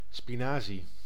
Ääntäminen
Ääntäminen France: IPA: /e.pi.naʁ/ Haettu sana löytyi näillä lähdekielillä: ranska Käännös Konteksti Ääninäyte Substantiivit 1. spinazie {m} kasvitiede Suku: m .